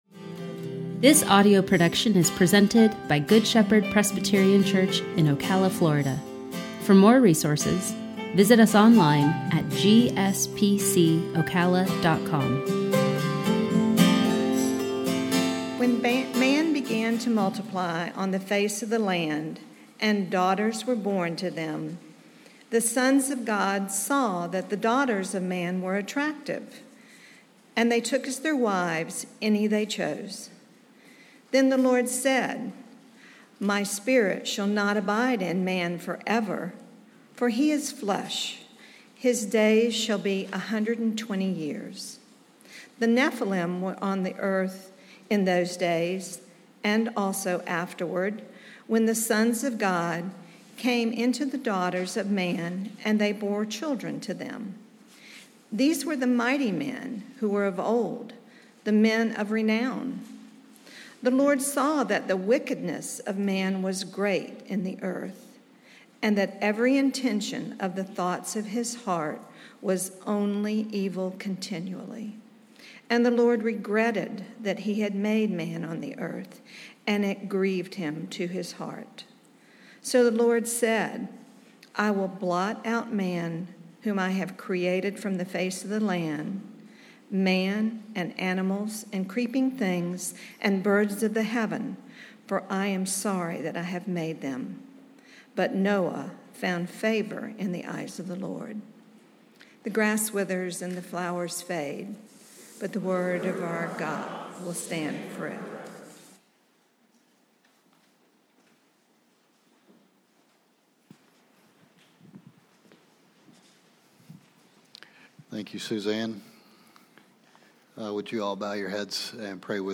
Sermon-7-4-21.mp3